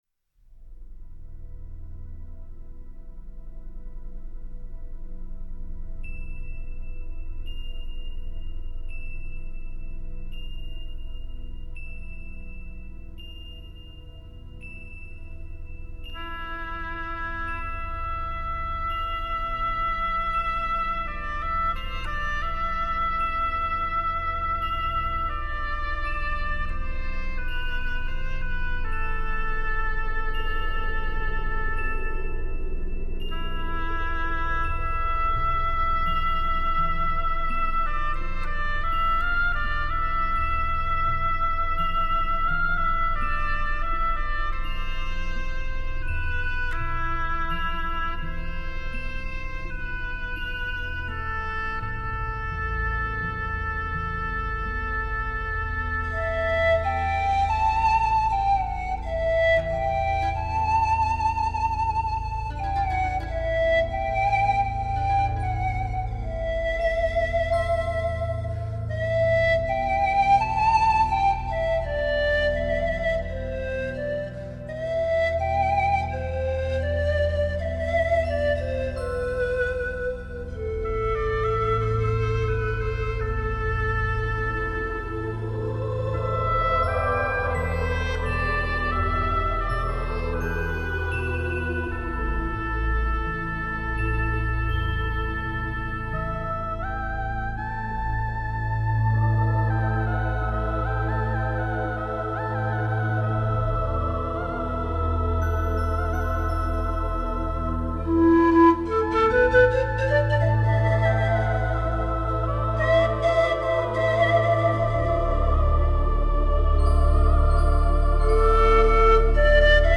HQCD的成品达到了接近原始音乐母带的完美音质。
运用真乐器和MIDI融合在一起
青海民歌